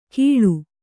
♪ kīḷu